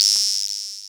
synTTE55017shortsyn-A.wav